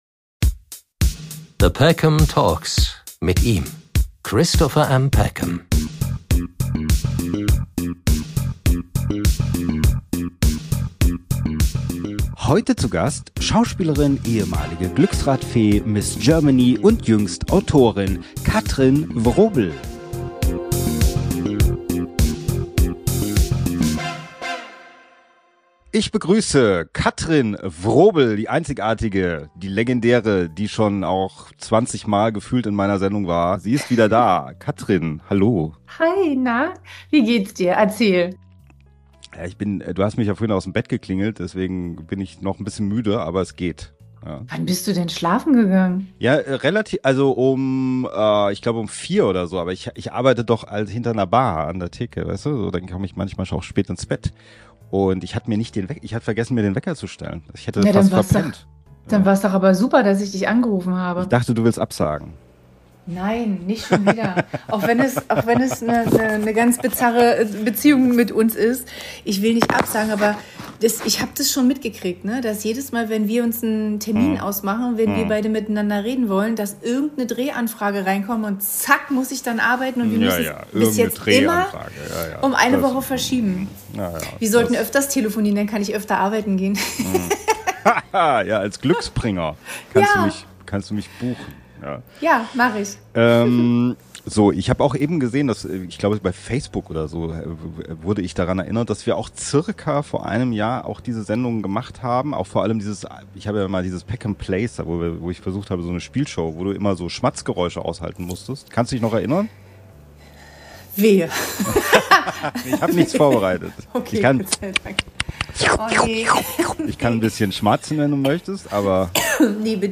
Stammgast Katrin Wrobel ist mal wieder da und präsentiert ihr neues Backbuch "Einfach backen"! Dazu eine Brise Politik, wir analysieren das Christkind - und holen das Tabuthema "Miet-Nikolaus" aus der Schmuddelecke! Frohe Weihnachten, ihr Leut - in diesem besinnlich witzigen Tortentalk!